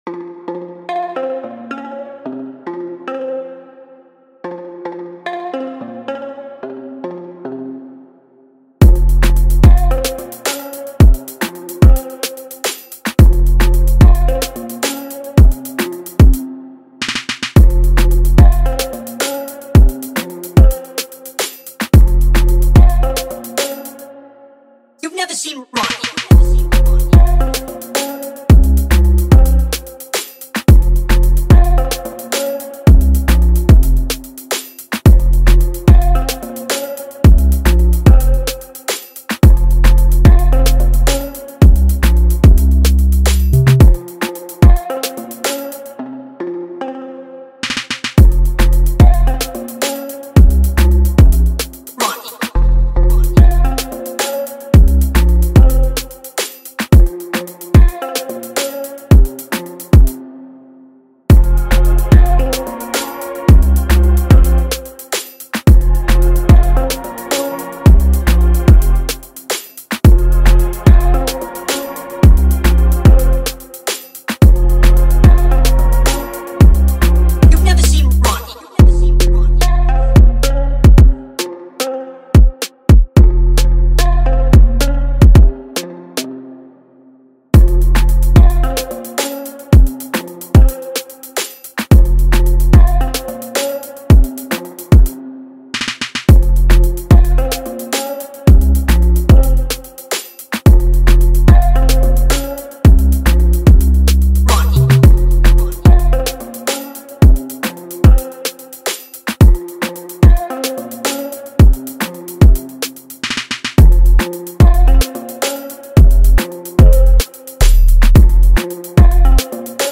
Download this free beat remake and make your music on it.